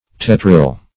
Search Result for " tetryl" : Wordnet 3.0 NOUN (1) 1. a yellow crystalline explosive solid that is used in detonators ; [syn: tetryl , nitramine ] The Collaborative International Dictionary of English v.0.48: Tetryl \Tet"ryl\, n. [Tetra- + -yl.]